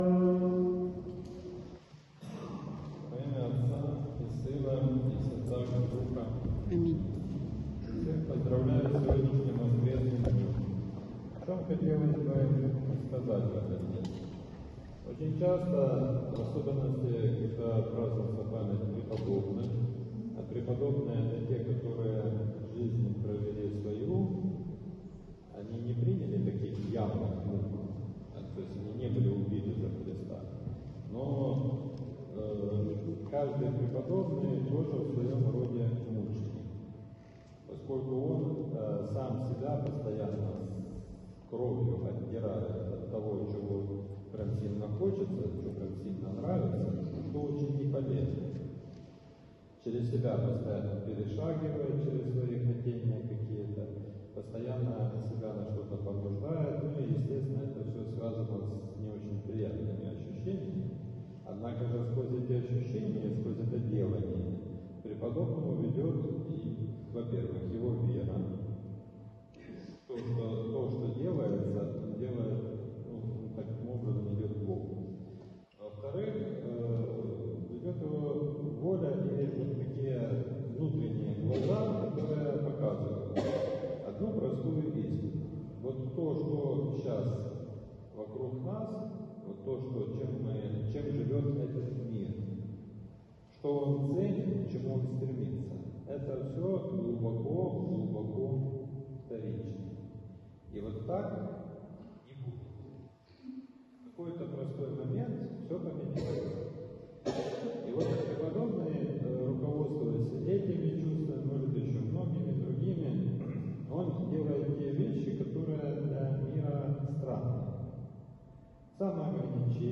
Проповедь в тринадцатую неделю по Пятидесятнице — Спасо-Преображенский мужской монастырь
В воскресение, 14 сентября, в тринадцатую неделю по Пятидесятнице, на Божественной Литургии читался отрывок из Евангелия от Луки(9:16-22).